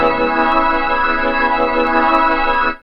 4507L BIGORG.wav